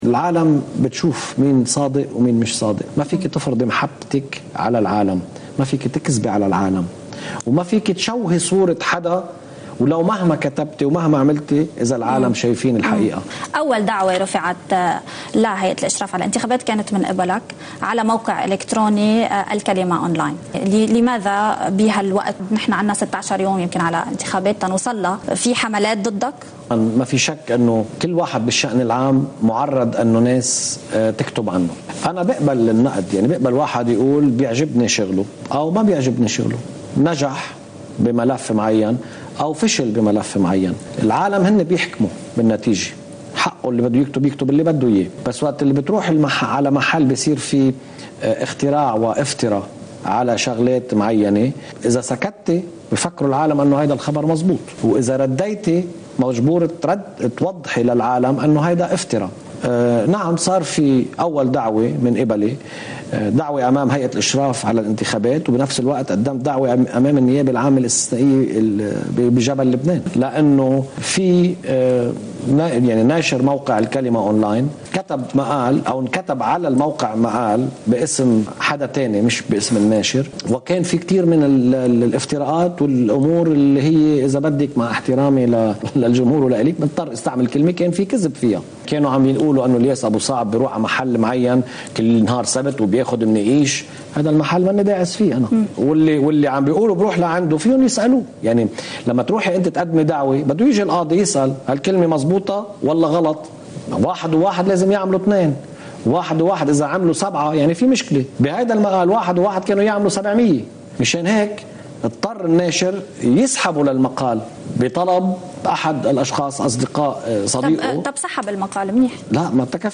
مقتطف من حديث الوزير السابق الياس بو صعب، المرشح عن المقعد الأرثوذكسي في دائرة المتن عن التيار الوطني الحر: (20 نيسان 2018)